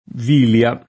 v&lLa]: la vilia di santa Maria d’agosto [la v&lLa di S#nta mar&a d ag1Sto] (G. Villani)